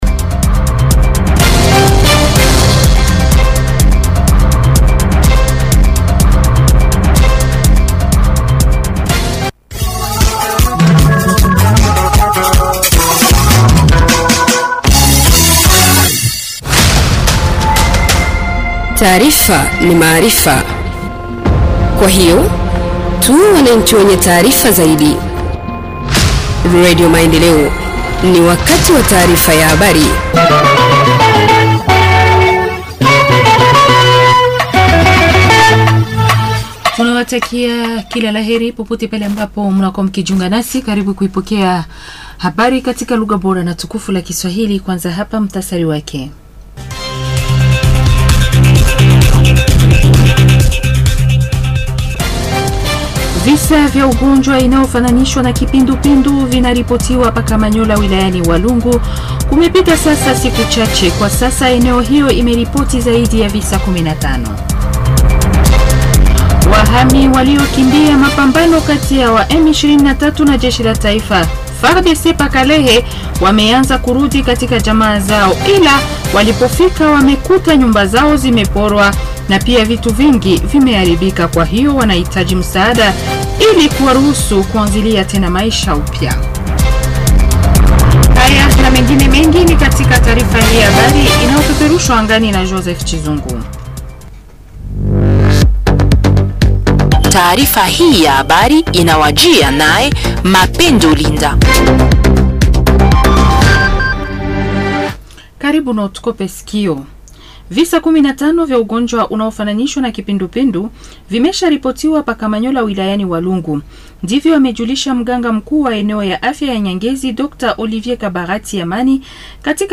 Journal en swahili du 25 février 2025 – Radio Maendeleo